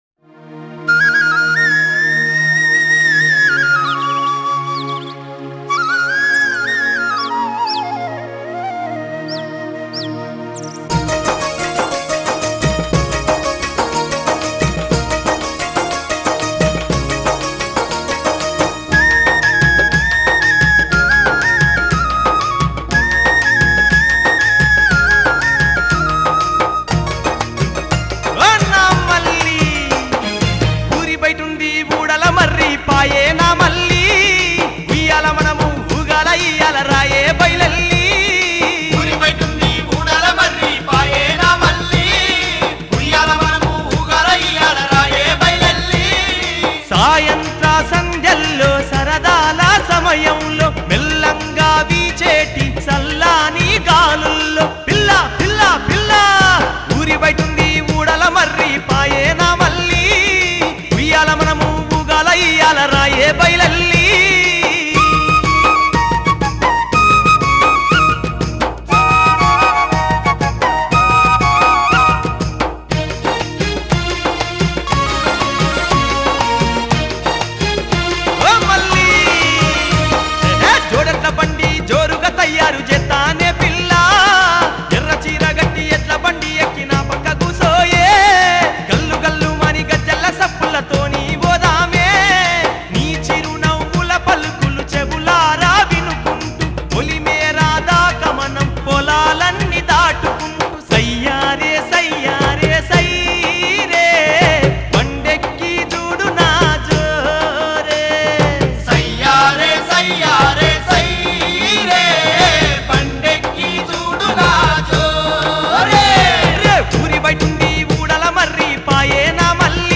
CategoryTelangana Folk Songs